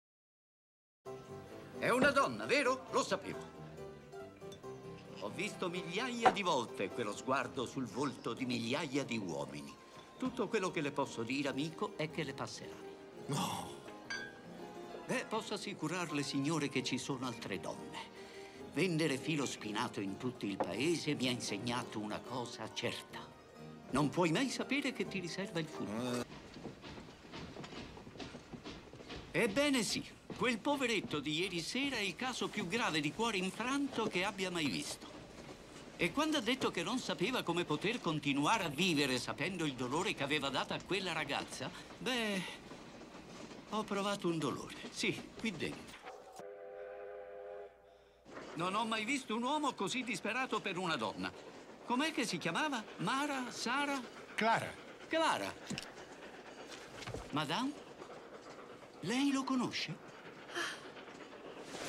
nel film "Ritorno al futuro - Parte III", in cui doppia Richard Dysart.